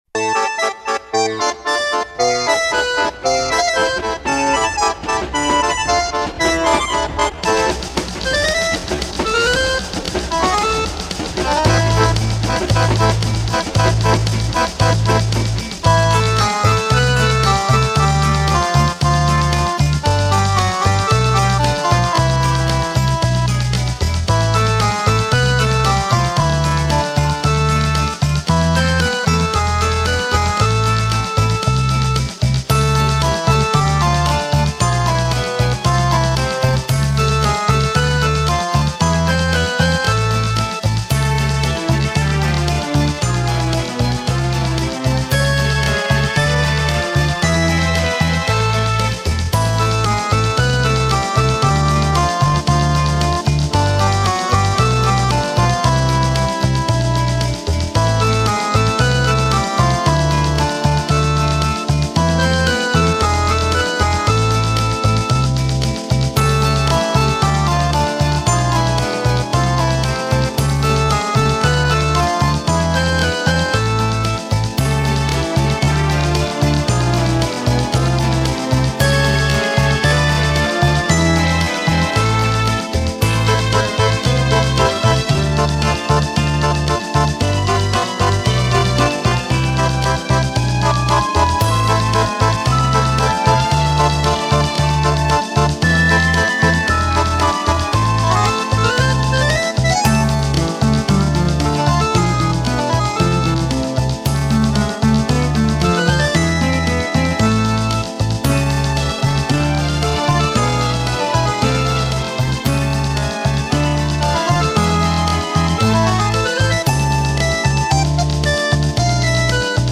Медленнее на 15%